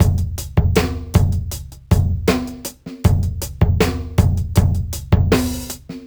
Index of /musicradar/sampled-funk-soul-samples/79bpm/Beats
SSF_DrumsProc1_79-02.wav